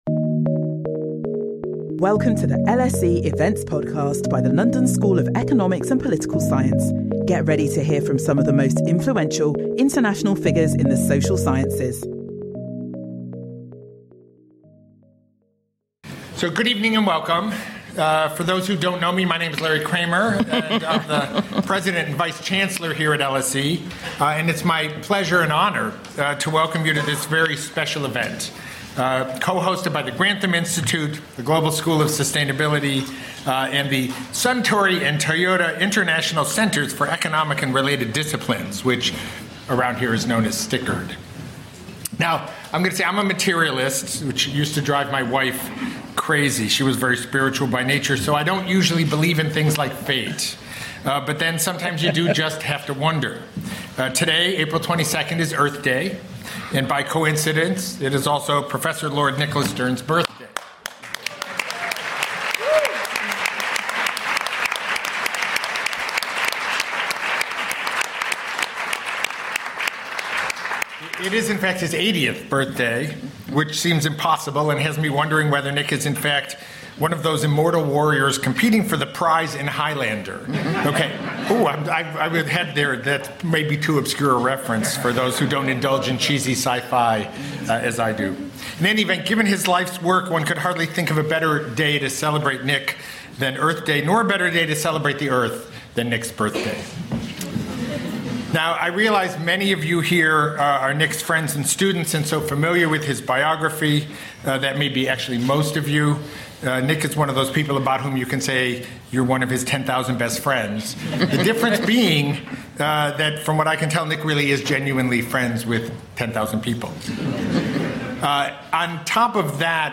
Listen to Nicholas Stern and world economic leaders in this LSE event discussion.
Global ideas for global challenges: a panel in honour of Nick Stern
This special public event was to mark Nicholas Stern's 80th birthday.